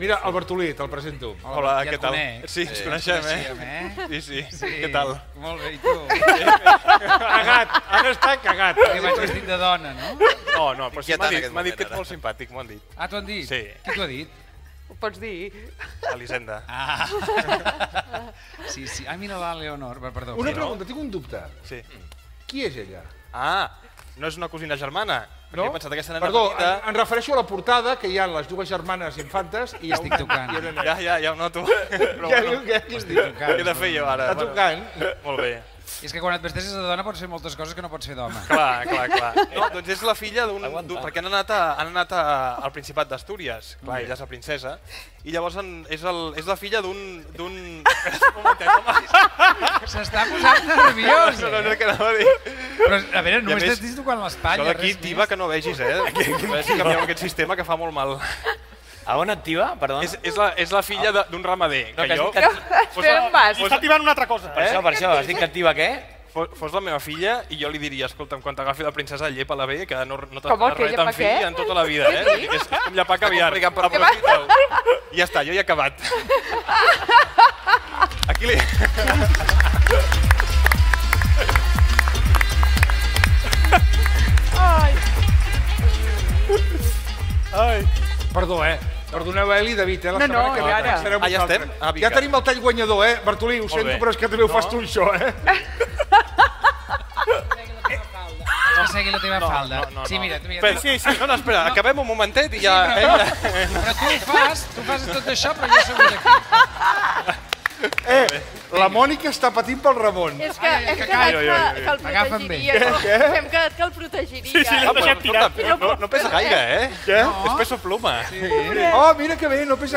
Programa fet al Teatre Tivoli, repàs a la premsa del cor, amb la intervenció de l'actor Àngel Llàcer, "el dialing"
Info-entreteniment